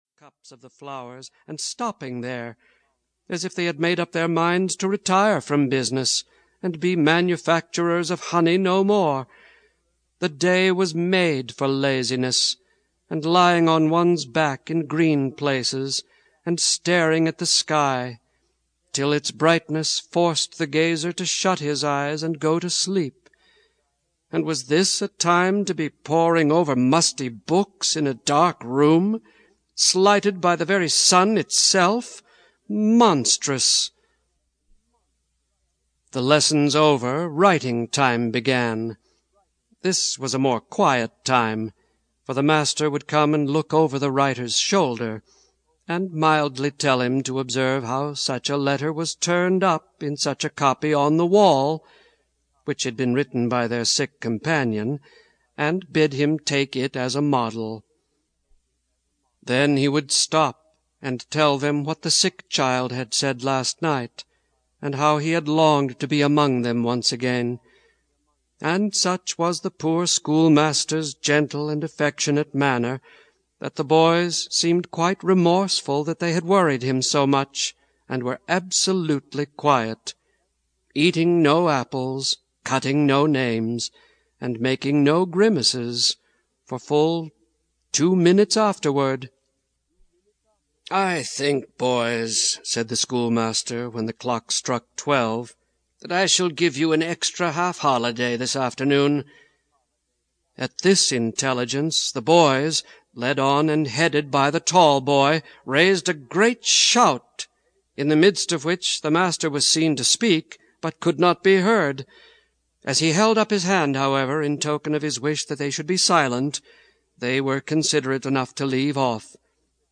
McGuffey’s Eclectic Readers: Sixth Audiobook
Narrator
12.8 Hrs. – Unabridged